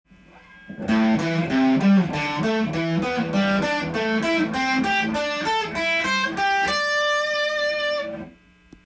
【5度で動くマイナーペンタトニックスケール】
譜面通り弾いてみました
①は6弦5フレットからAmペンタトニックスケールを１音弾くたびに
その音に対する５度の音を弾くスケール練習になっています。